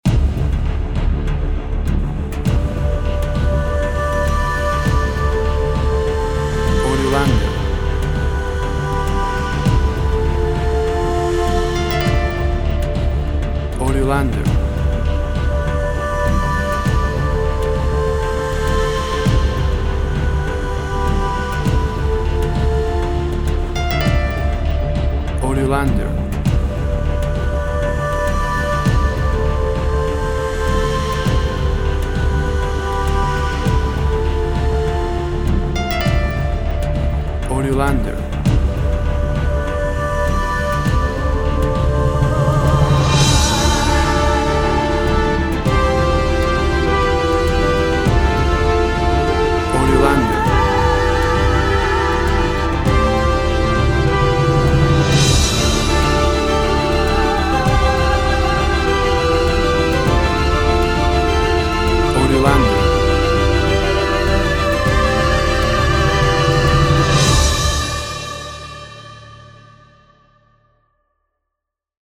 Female choirs sing epic times of eternity walking to glory.
Tempo (BPM) 100